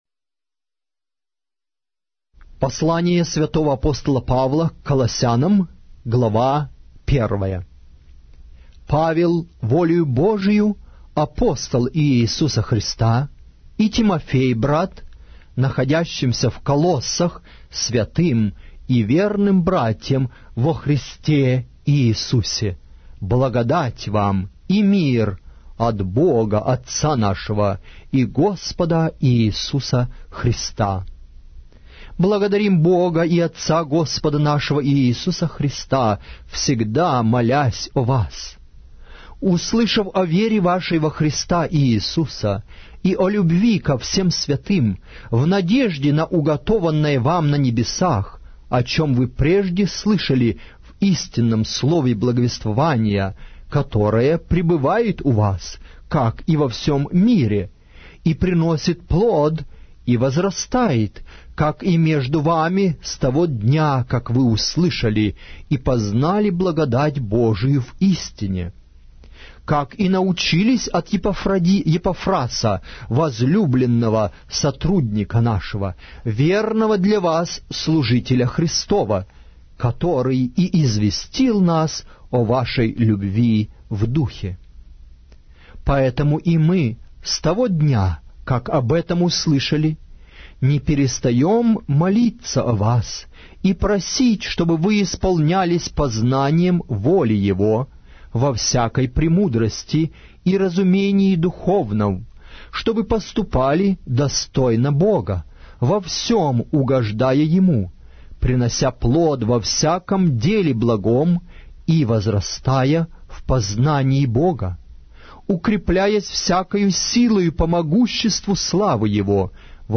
Аудиокнига: Посл.Св. Апостола Павла. Колоссянам